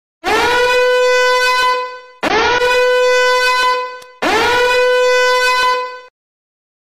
Siren / Zero Credit Meme Sound Effects Free Download